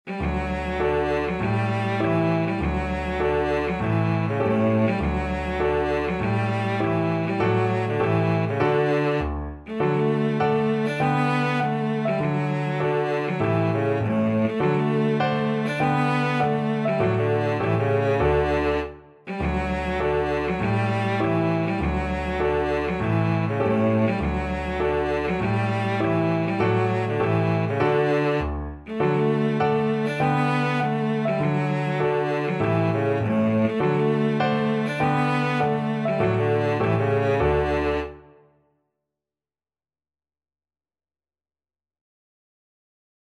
Cello
The Keel Row is a traditional Tyneside folk song evoking the life and work of the keelmen of Newcastle upon Tyne.
D major (Sounding Pitch) (View more D major Music for Cello )
Allegretto